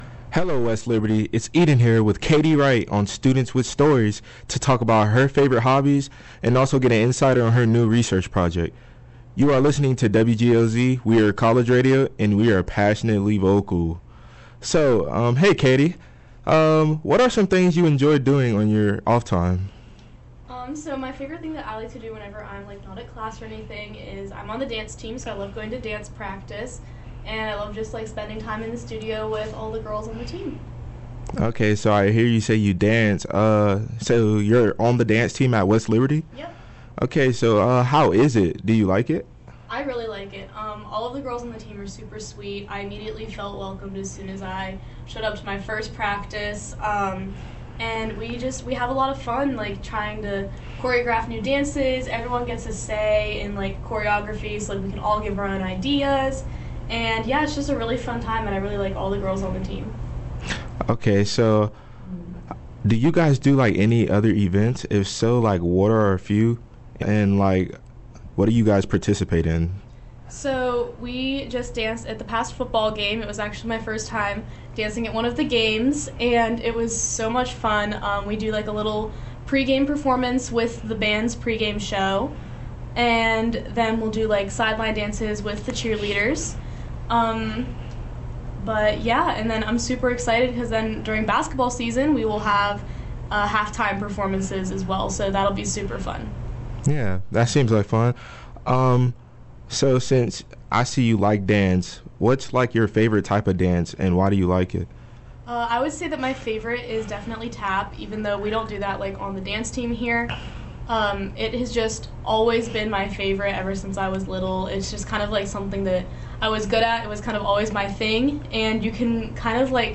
In recent interview